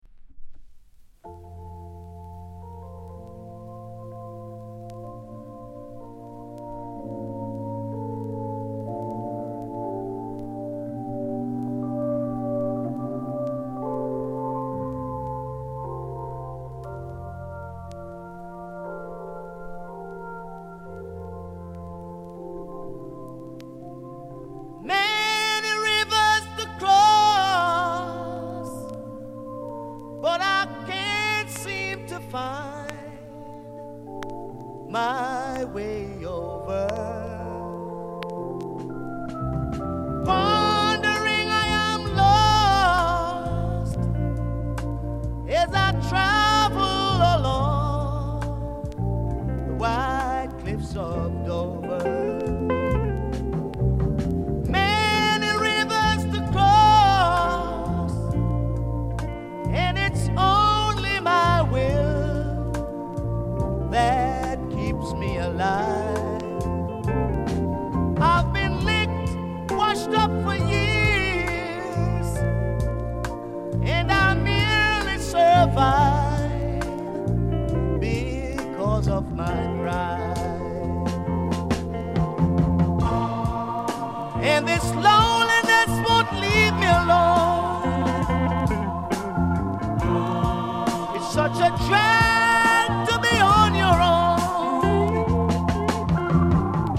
フランス 7inch/45s
盤：EX-。大変良好です。薄いキズ or 擦れ 程度。大変キレイです！